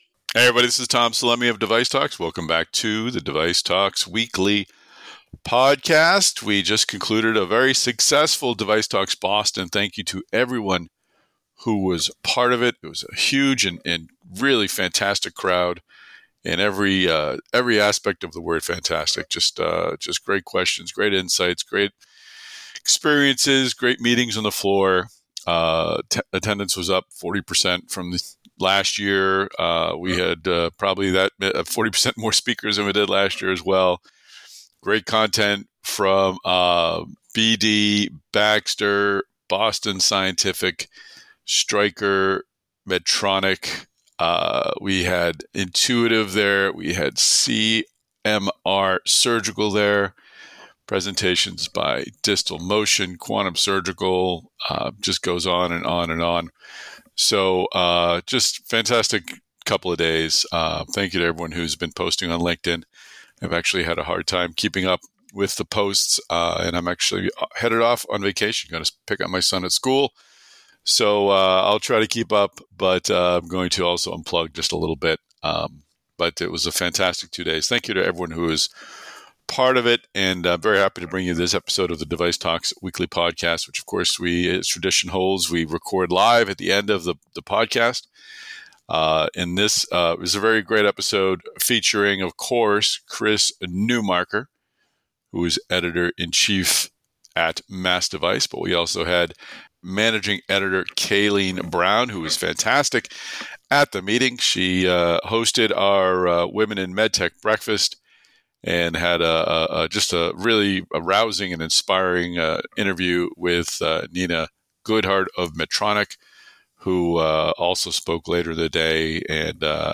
Hear the Final Word About DeviceTalks Boston: Live Recording of DeviceTalks Weekly On Stage